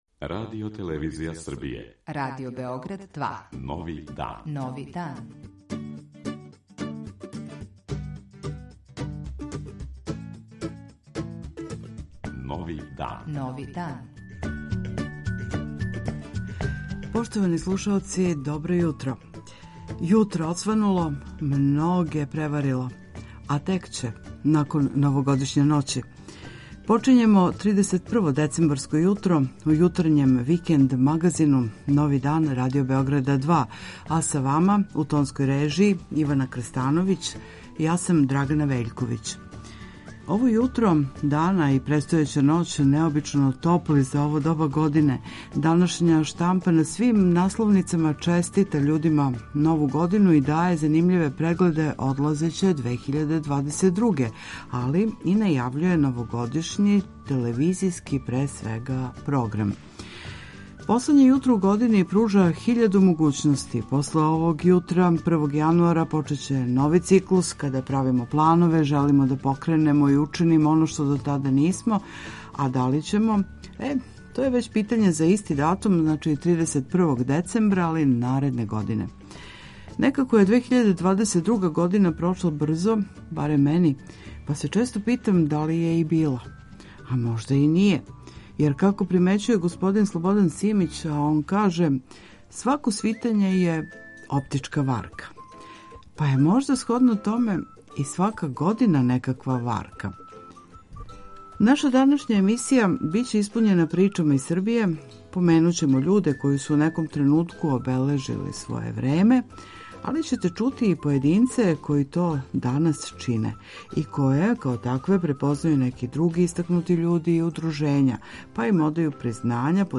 Редовне рубрике и сервисне информације.